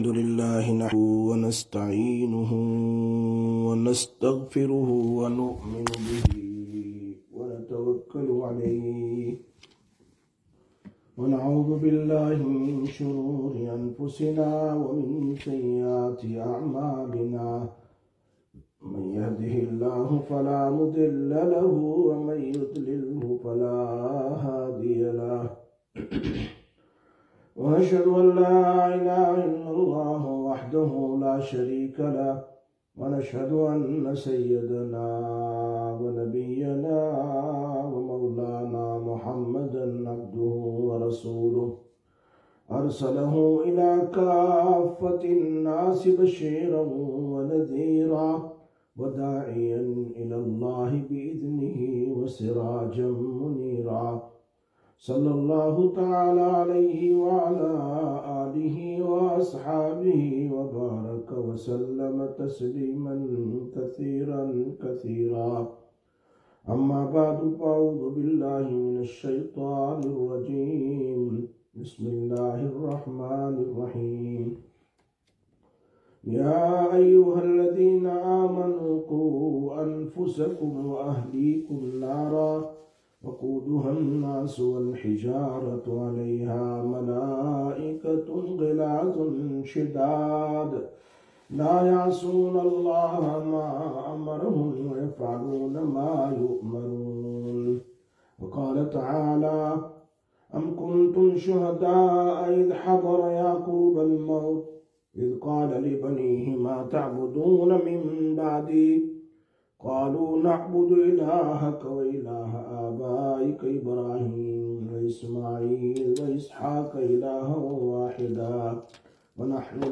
16/04/2025 Sisters Bayan, Masjid Quba